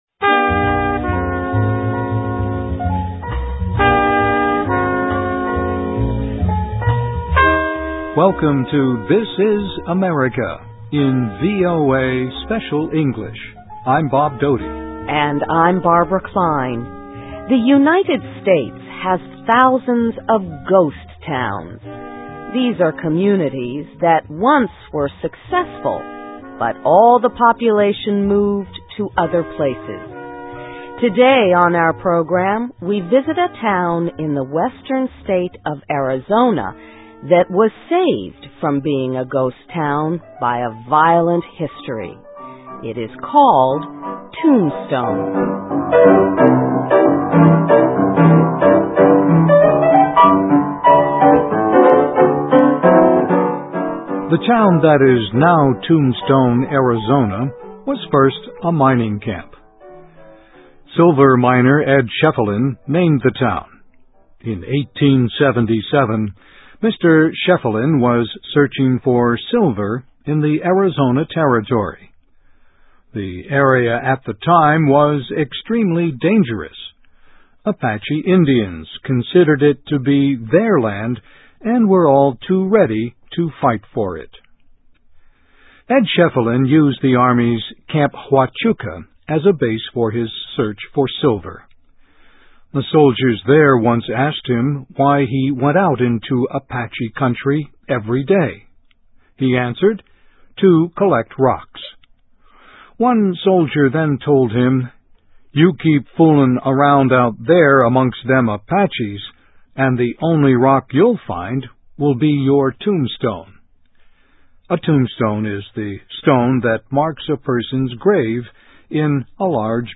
USA: Tombstone, Arizona: 'The Town Too Tough to Die' (VOA Special English 2008-10-18)<meta name="description" content="Text and MP3 File.
Listen and Read Along - Text with Audio - For ESL Students - For Learning English